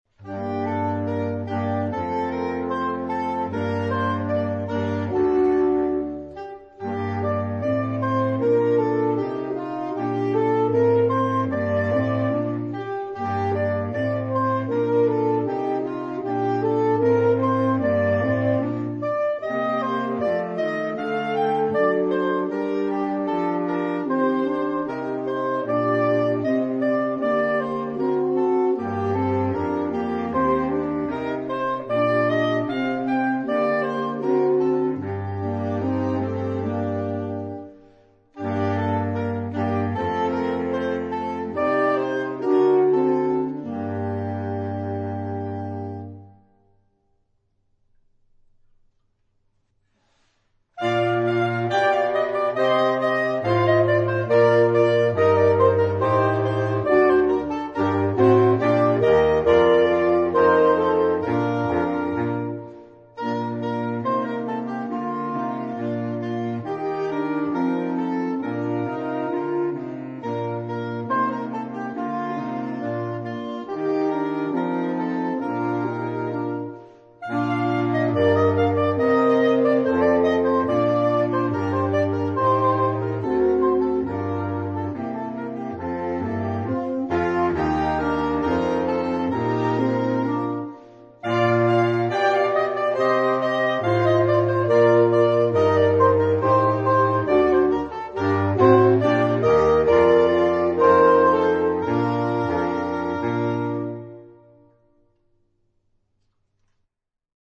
Gattung: für Holzbläserquartett